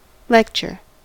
lecture: Wikimedia Commons US English Pronunciations
En-us-lecture.WAV